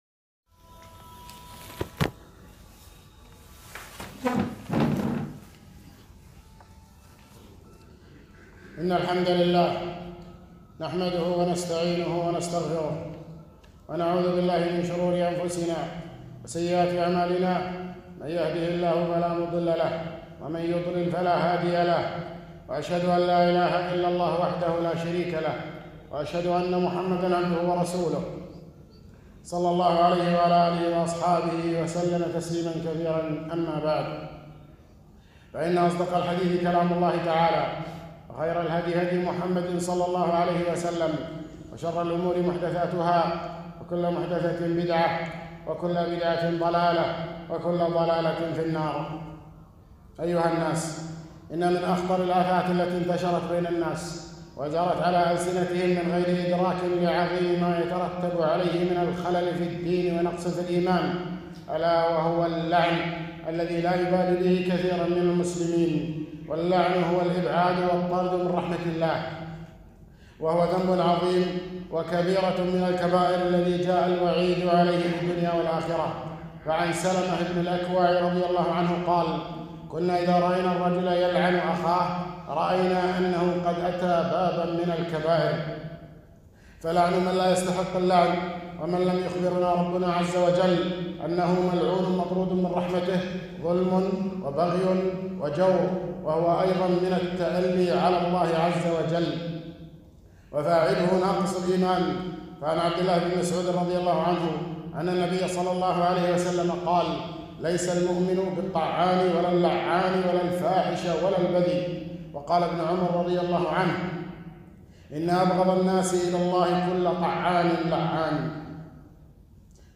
خطبة - التحذير من اللعن